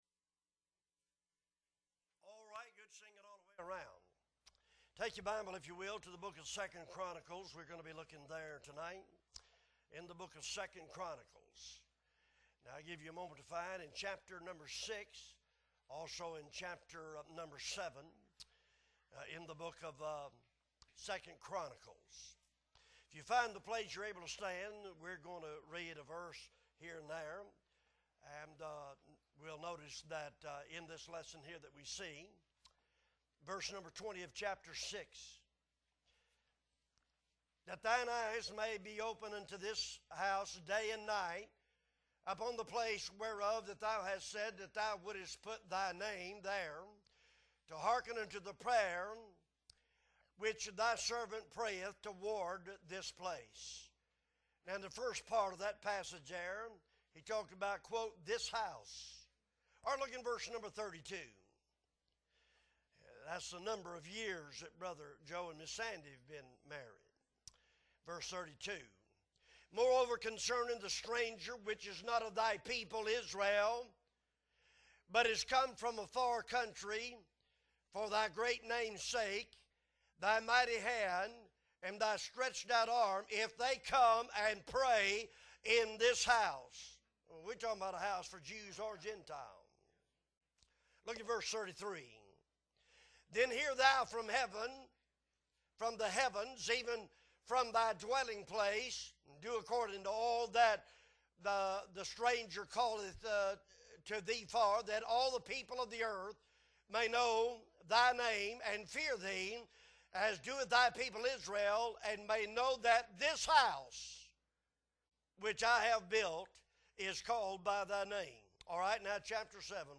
September 18, 2022 Evening Service - Appleby Baptist Church